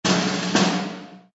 SZ_MM_drumroll.ogg